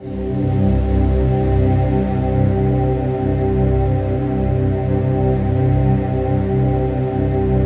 DM PAD1-08.wav